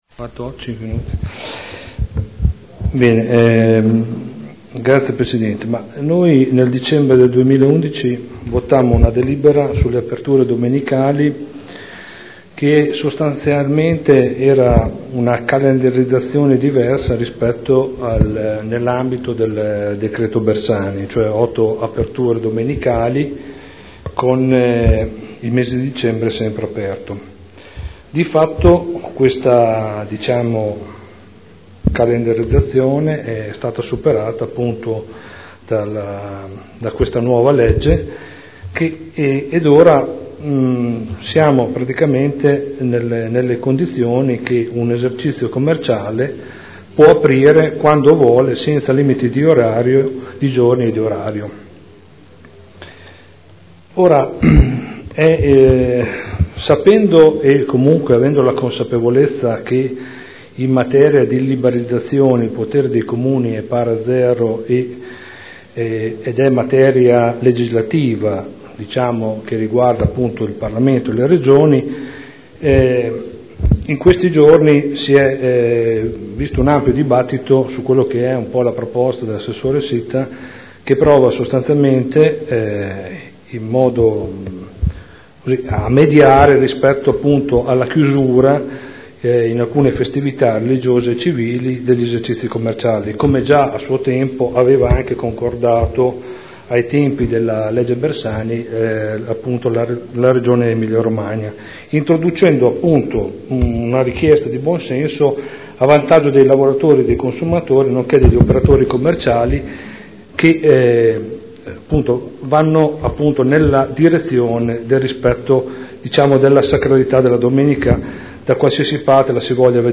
Seduta del 24/09/2012 Dibattito su Odg 27661 - Odg 33446 - Mz 1362 - Mz 111648.